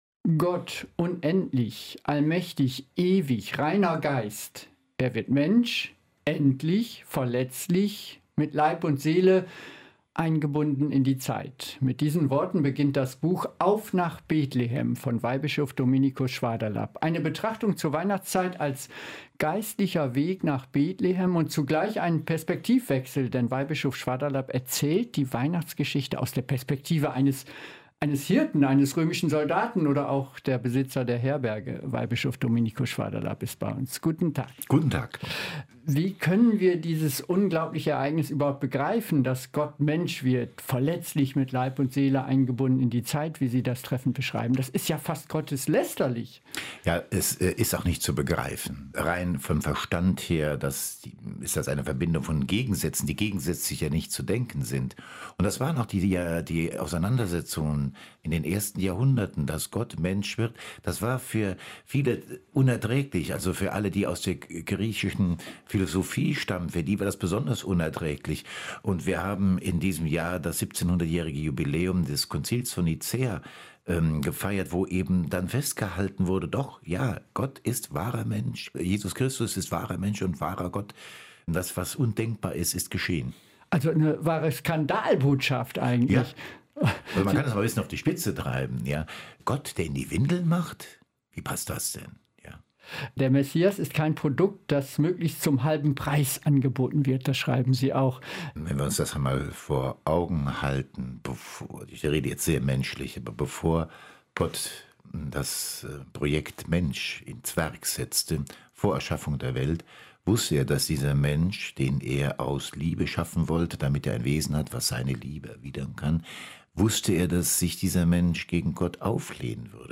Weihbischof Schwaderlapp spricht über sein Weihnachtsbuch